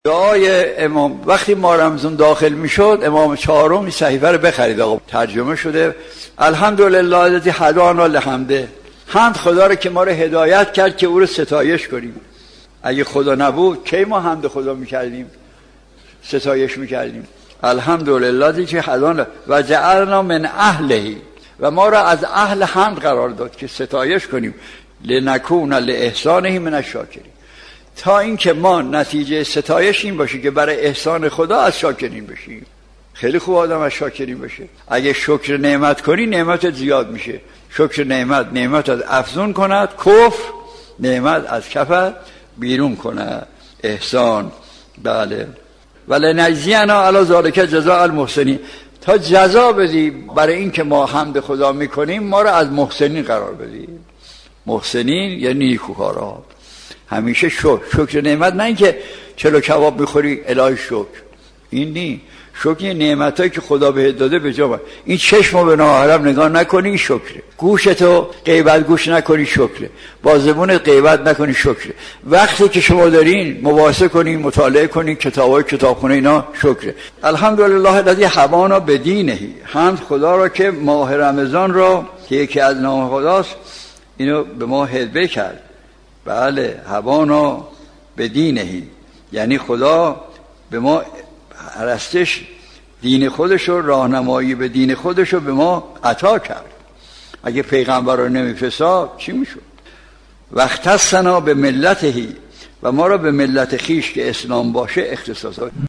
مجموعه حاضر سلسله درس های اخلاق و احکام آیت الله مجتهدی تهرانی می باشد .